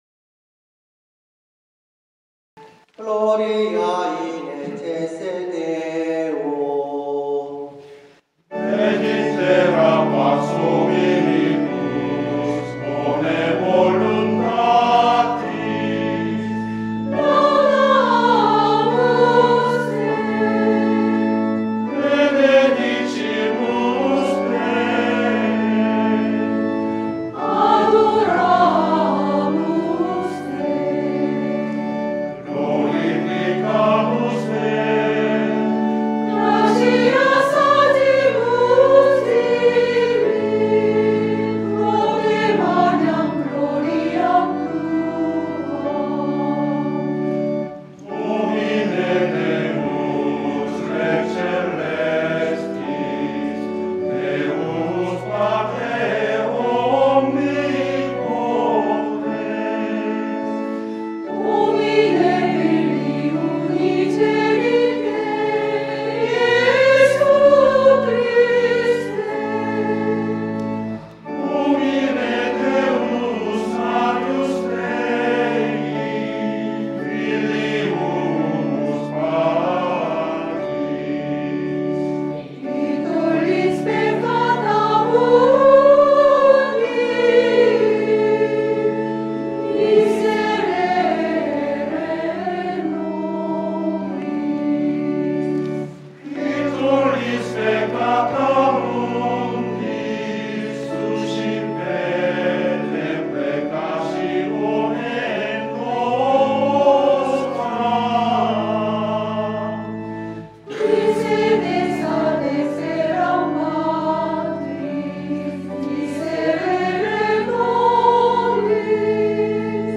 Mass of the Angels, Gregorian Chant